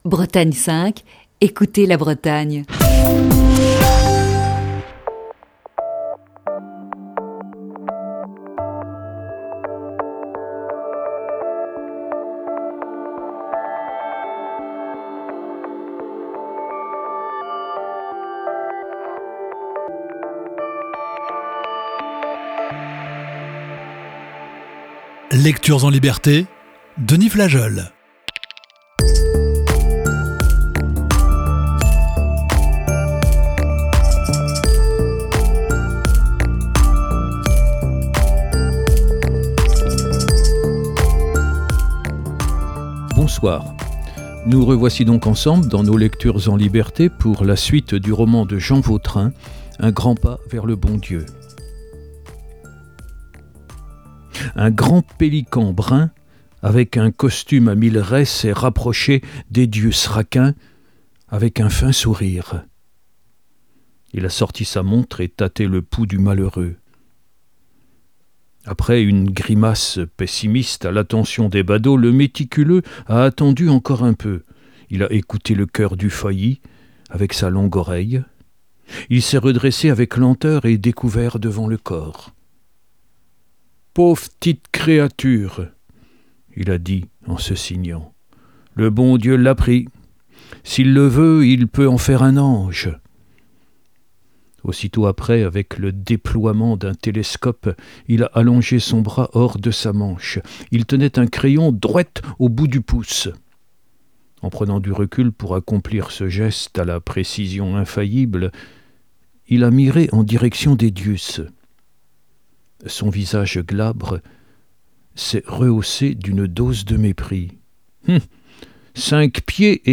Émission du 14 avril 2021.